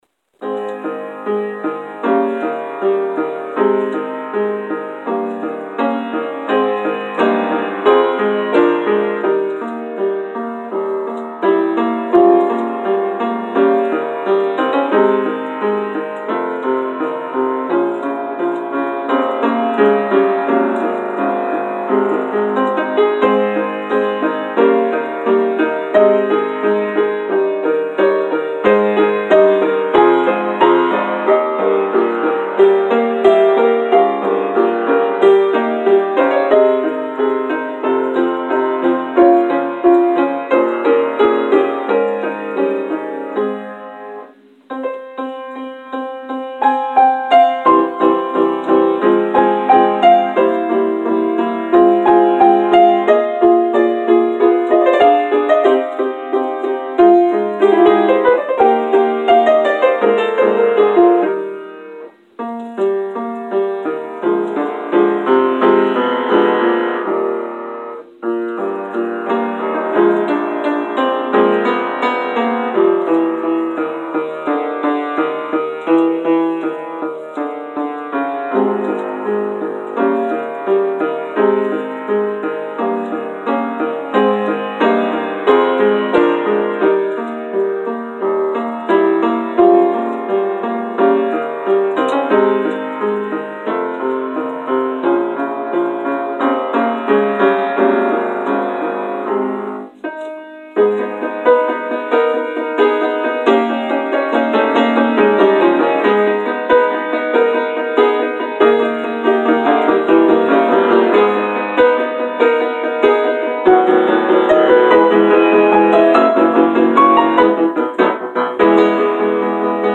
Prelude: “Adagio cantabile” from Pathetique Sonata – Ludwig van Beethoven